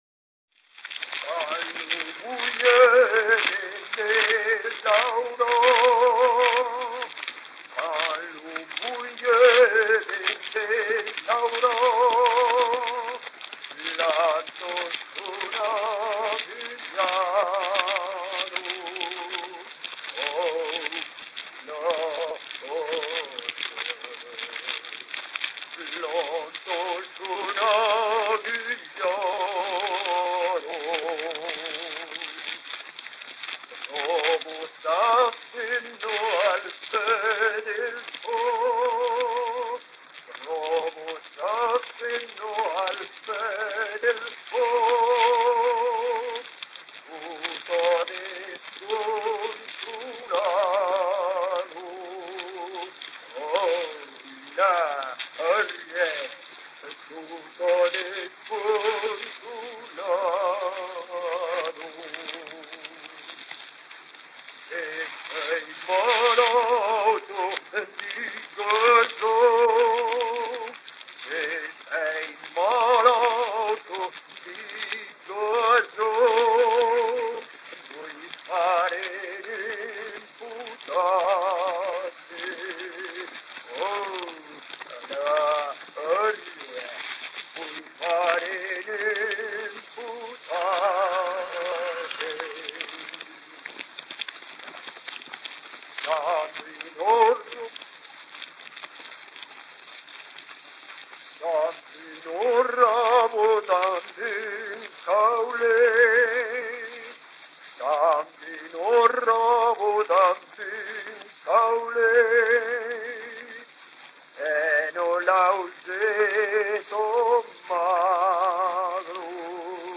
collecté le 05/02/1942 à Toulouse
Version voix soliste